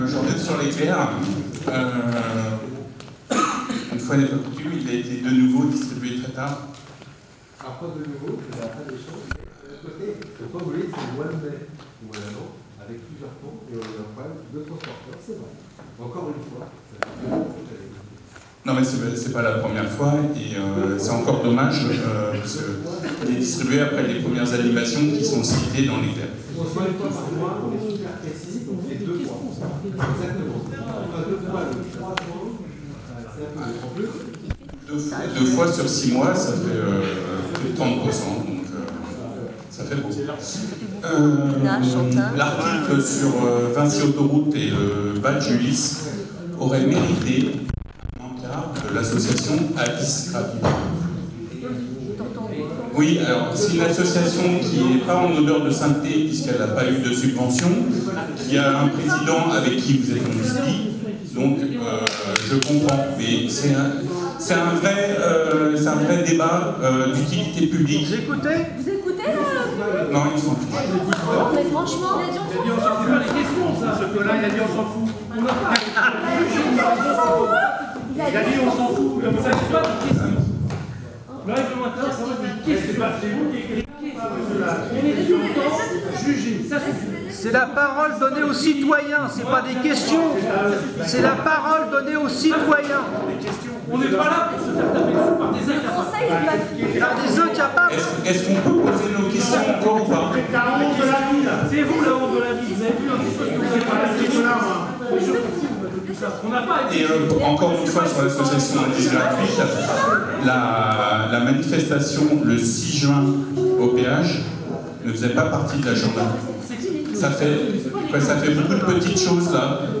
30 minutes sont consacrées à l'expression des citoyen(ne)s avec éventuellement des questions (qui n'obtiennent que de rares fois une réponse des élu(e)s de la majorité).
Un citoyen aborde sereinement trois sujets: